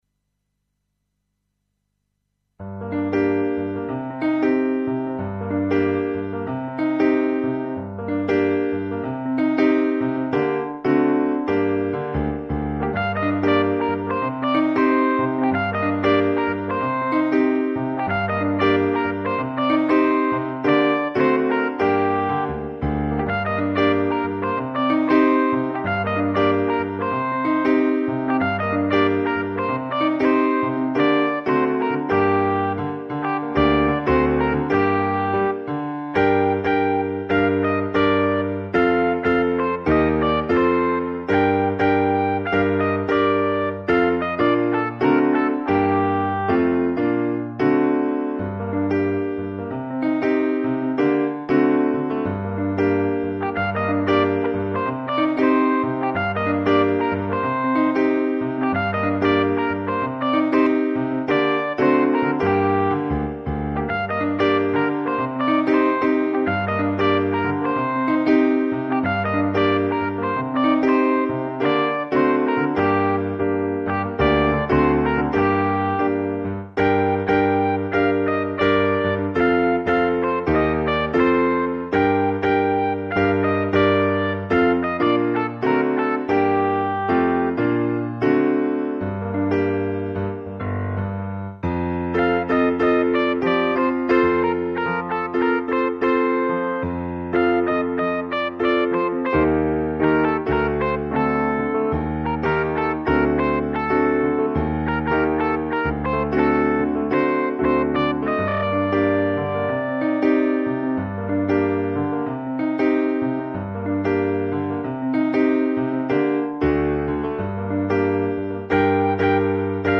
Le karaoké